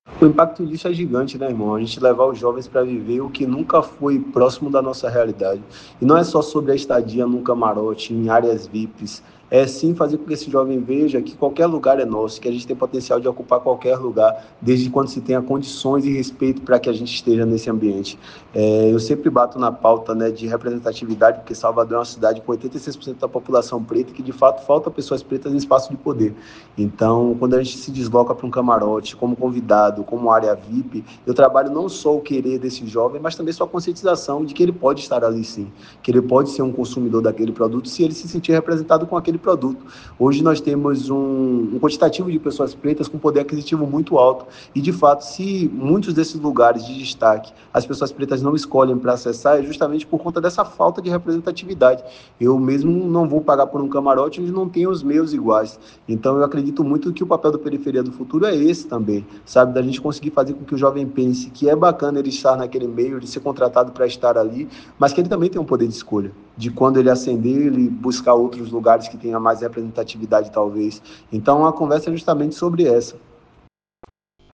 Breaking News